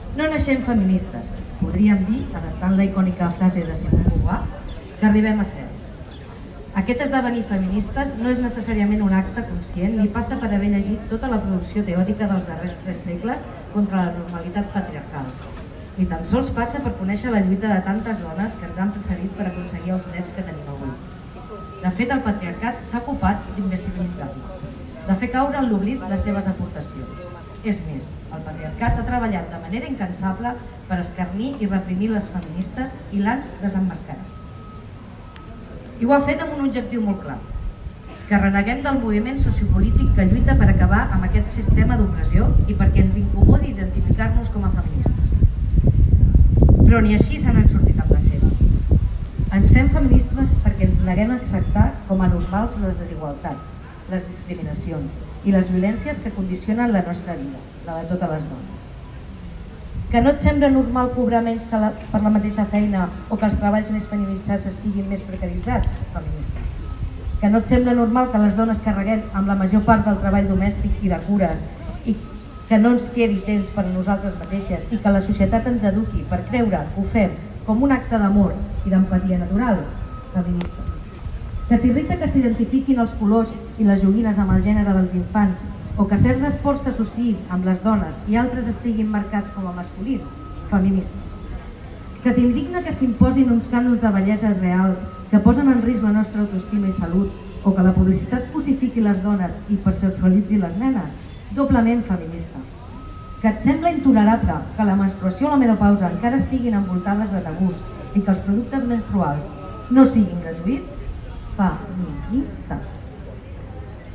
En el cas de Sant Feliu de Guíxols, l’alcalde del municipi, Carles Motas, va ser l’encarregat d’obrir l’acte de lectura del manifest. Però va ser la regidora d’Acció Social de l’ajuntament, Laura Serrano, la que va fer la lectura del manifest.